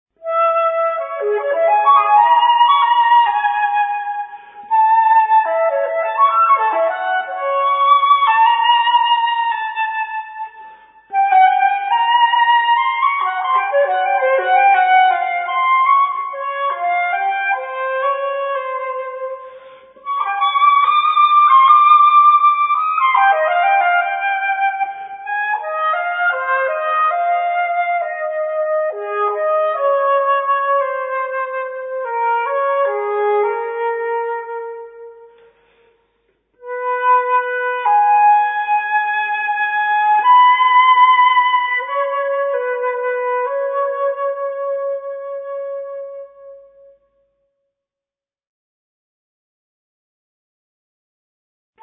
Zwölf Rubato-Stückchen für Flöte solo